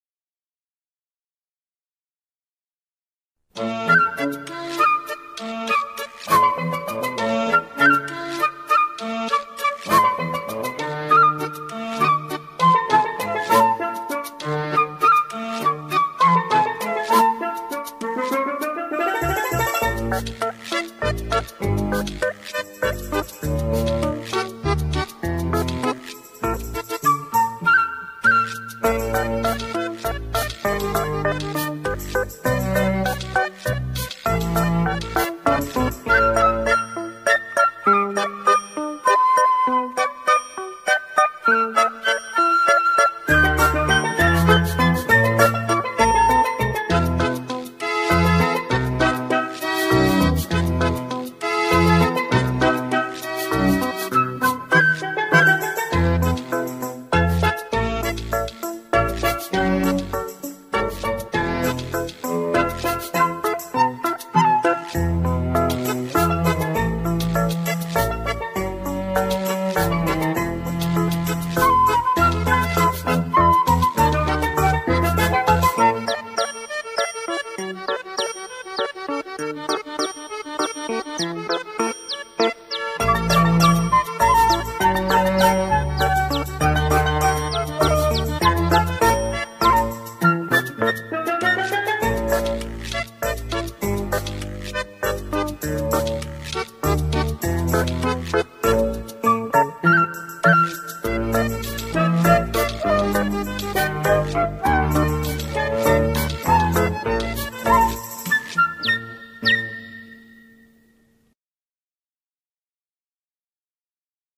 سرود های کودک
بی‌کلام